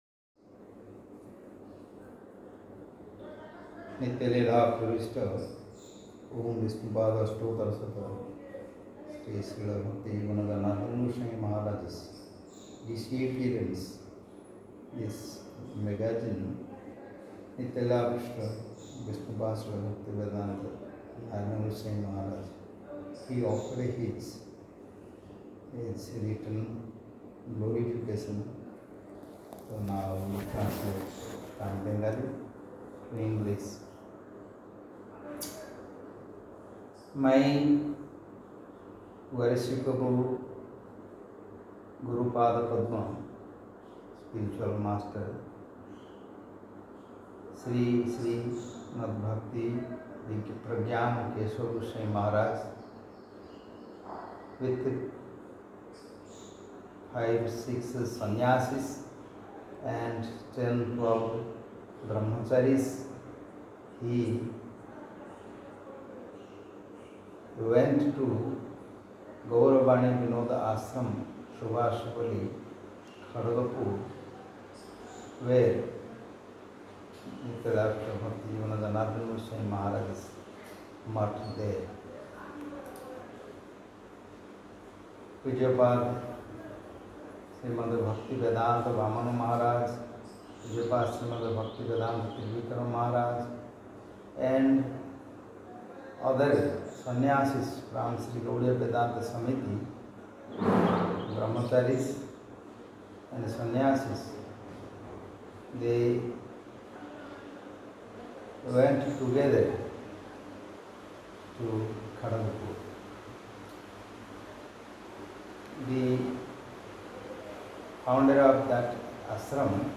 Unedited Audio Recording
BVBM-Dictation-4.mp3